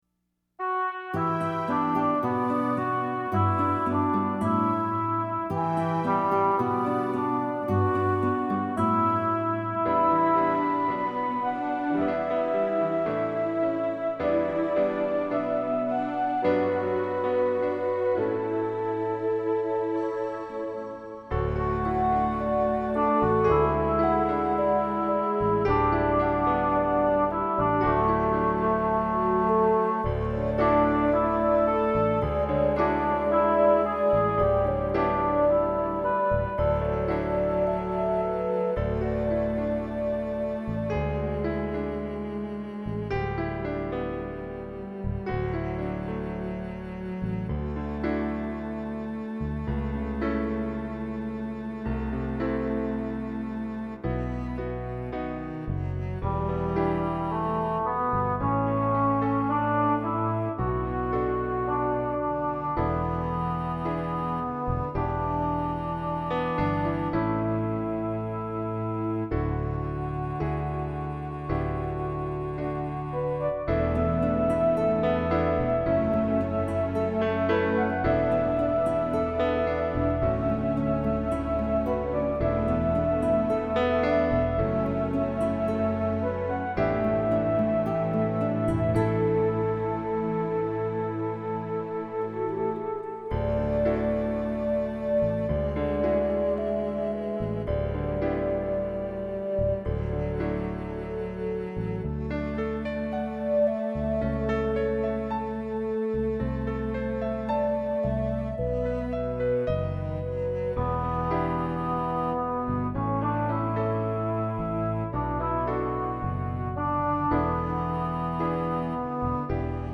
There are musical interludes in it that easily touch my heart and make me cry.
KARAOKE